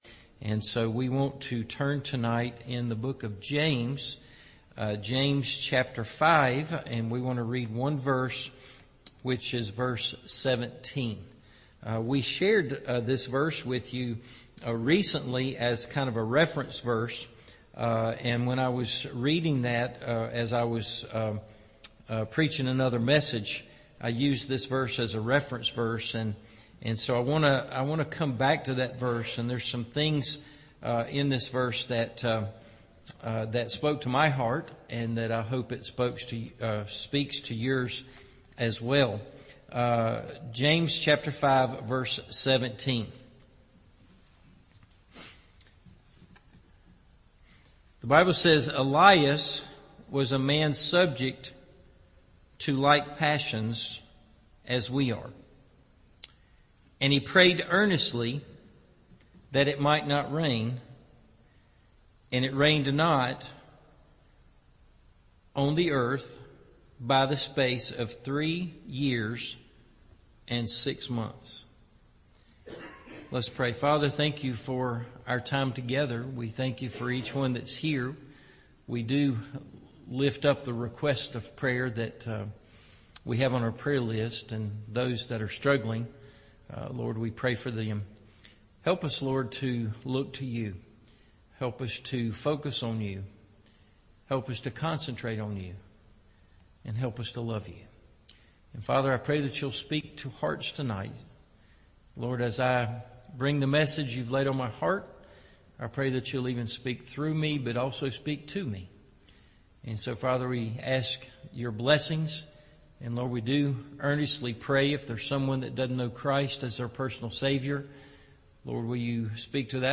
Elijah’s Secret – Evening Service